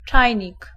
Ääntäminen
IPA : /ˈkɛtəl/ IPA : [ˈkɛ.ɾl̩]